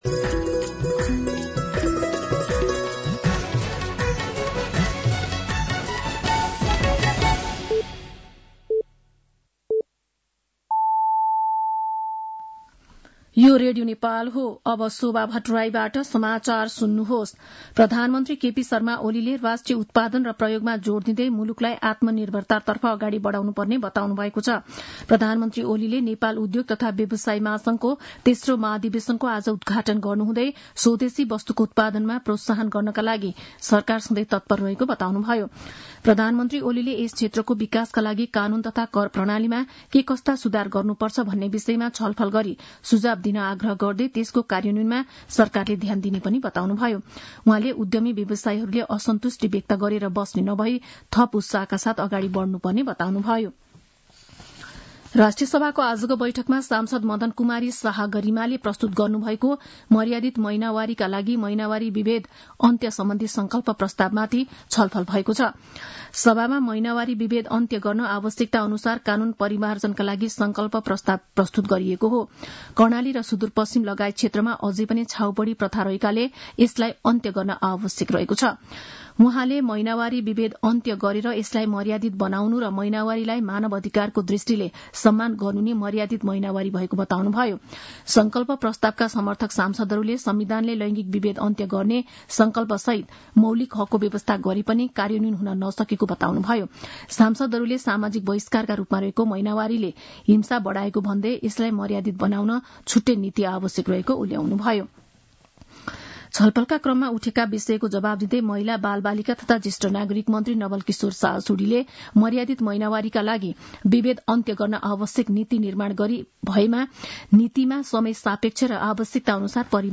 साँझ ५ बजेको नेपाली समाचार : ८ चैत , २०८१